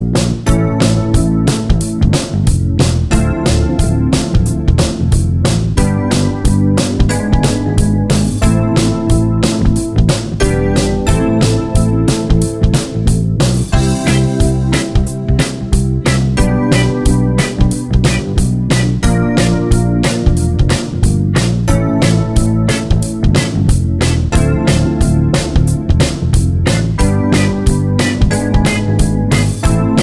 Two Semitones Down Pop (1980s) 4:23 Buy £1.50